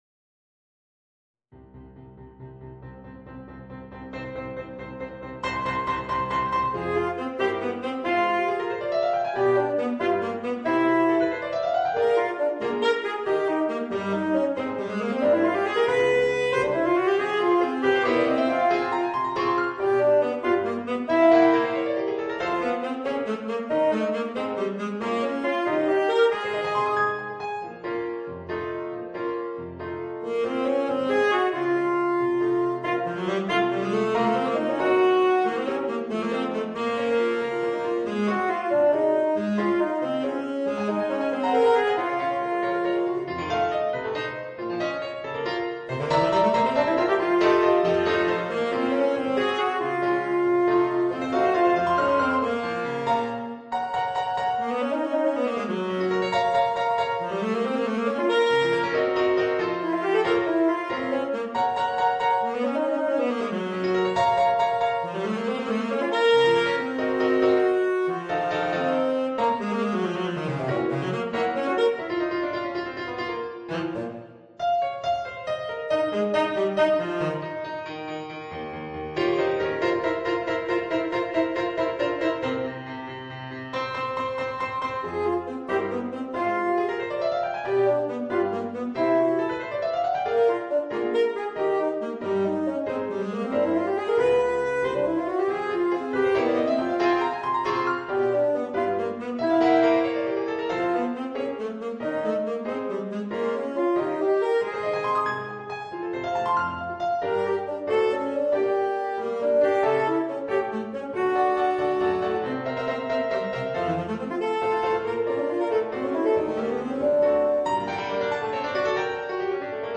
Saxophone ténor & piano